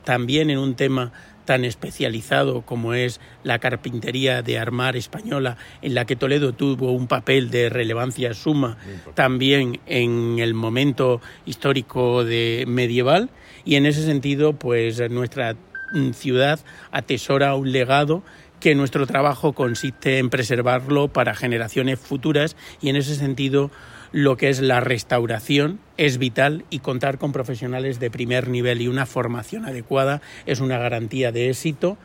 teo-garcia_curso-carpinteria-de-armar.mp3